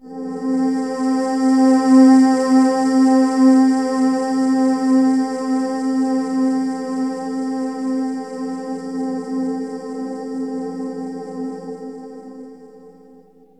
AMBIENT ATMOSPHERES-5 0008.wav